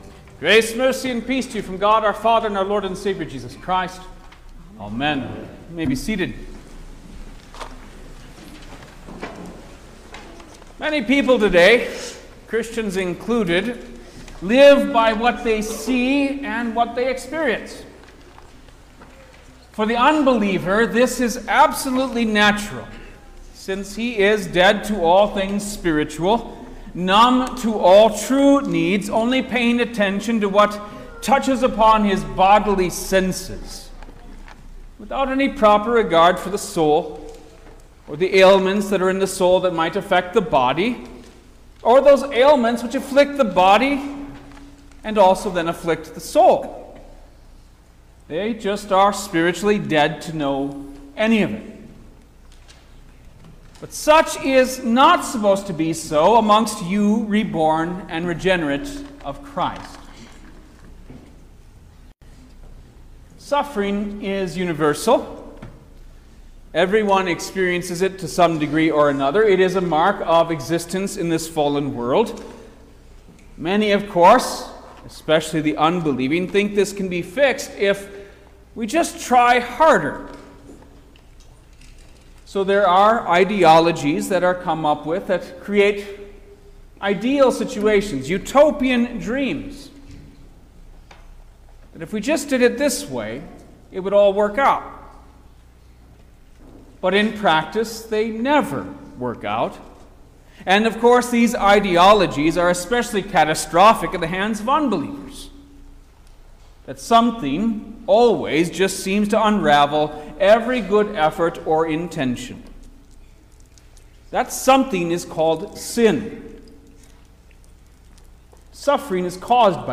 October-6_2024_Nineteenth-Sunday-after-Trinity_Sermon-Stereo.mp3